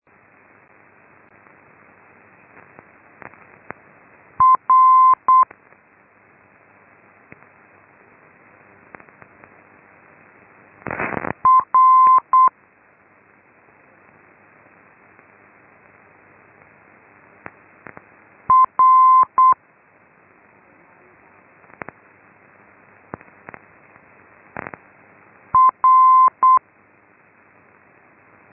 Morse Telegraphy